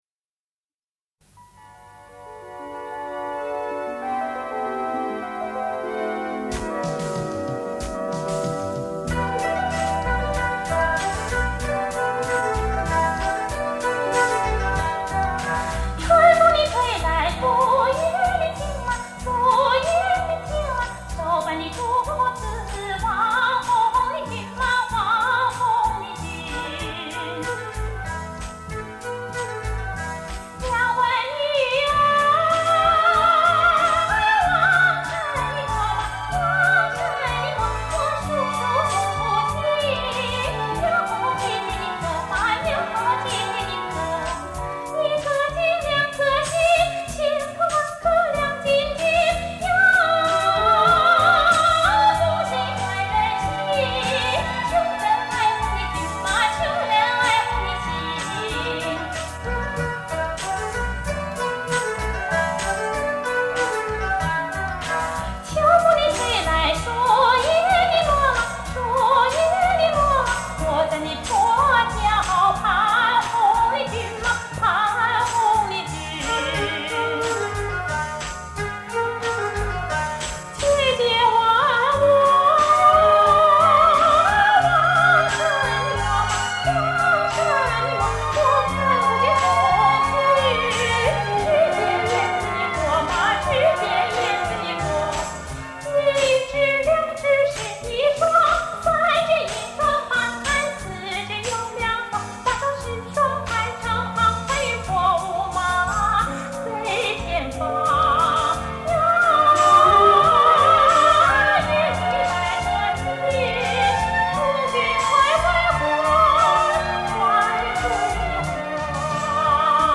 滇西民歌